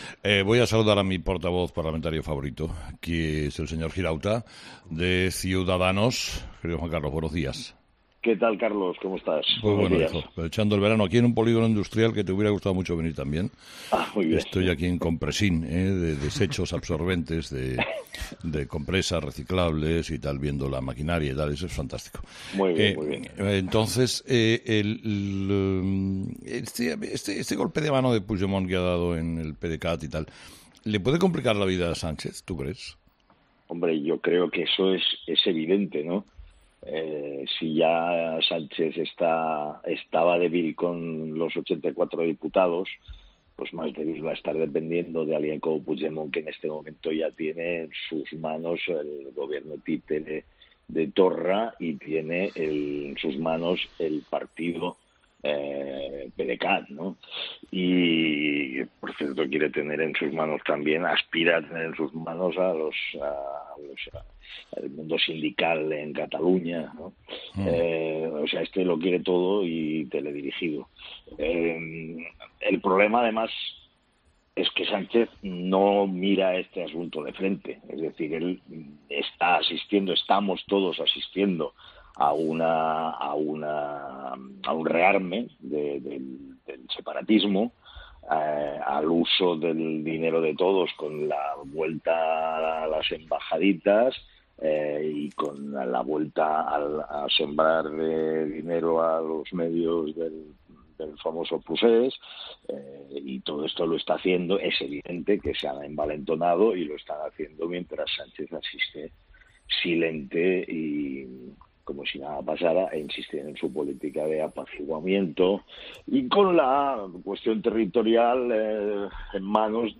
Juan Carlos Girauta, portavoz de Ciudadanos en el Congreso de los Diputados, ha participado en 'Herrera en COPE' para comentar la situación actual de Cataluña tras la llegada al poder de Puigdemont en el PdeCat, y la situación del partido tras la llegada de Pablo Casado a la dirección del Partido Popular.